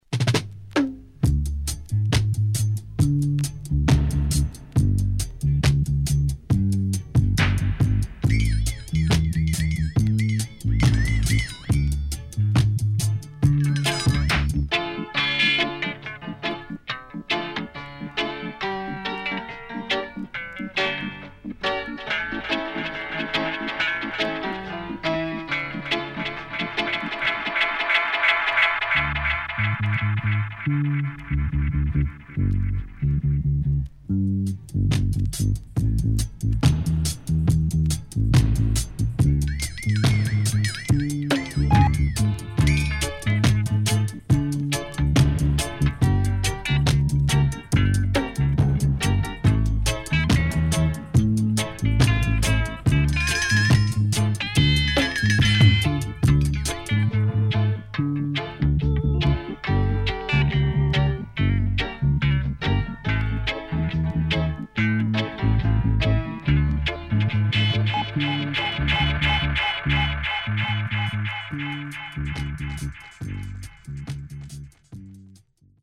HOME > REISSUE [DANCEHALL]
Rare.Deep Vocal